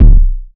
Waka KICK Edited (42).wav